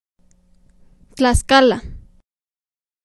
^ UK: /tləˈskɑːlə, tlæˈ-/ tlə-SKAH-lə, tla-, US: /tlɑːˈ-/ tlah-; Spanish: [tla(ɣ)sˈkala]
TlaxcalaPronunciation.ogg.mp3